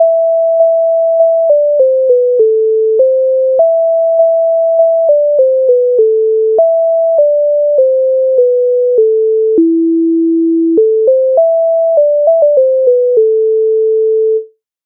MIDI файл завантажено в тональності a-moll
Чого соловей смутен Українська народна пісня з обробок Леонтовича с.196
Ukrainska_narodna_pisnia_Choho_solovej_smuten.mp3